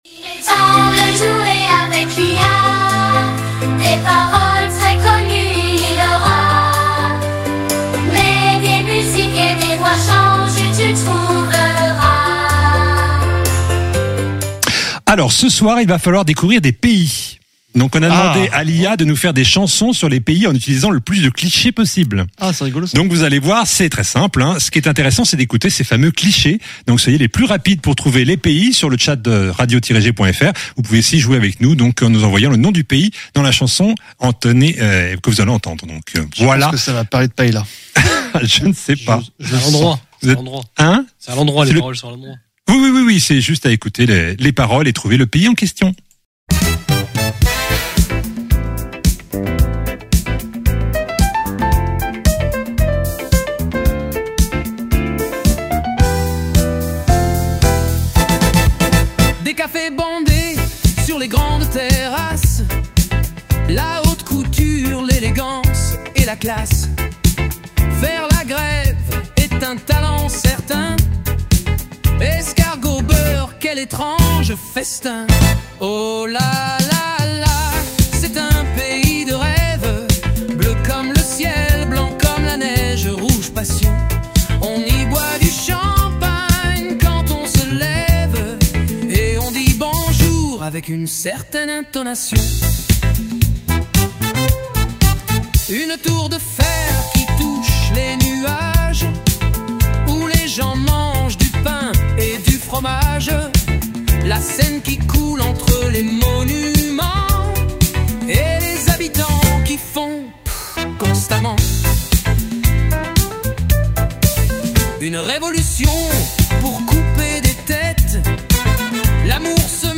Jouons à trouver des pays avec leur clichés chantés